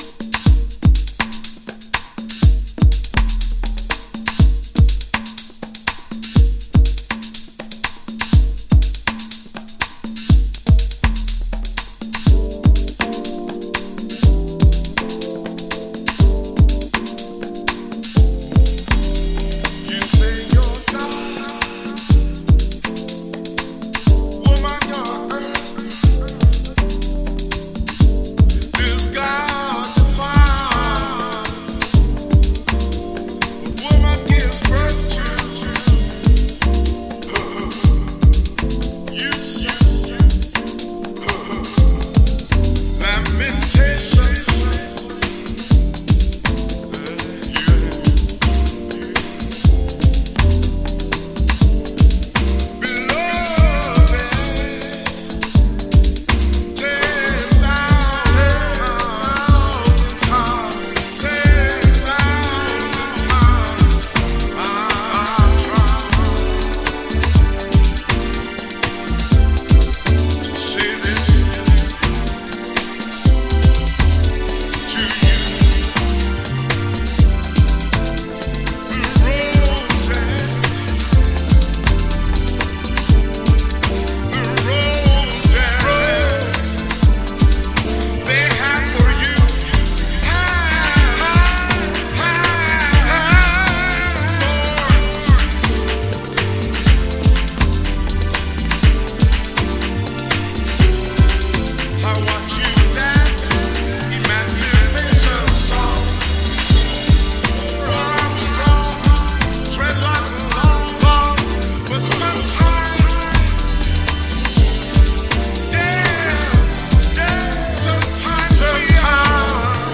House / Techno
ポエトリーも歌声もどちらも沁みます。
Full Vocal Mix